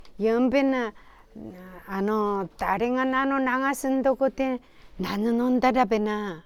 Aizu Dialect Database
Final intonation: Falling
WhP1 Intonation: Peak (Higher)
WhP2 Intonation: Peak
Location: Aizumisatomachi/会津美里町
Sex: Female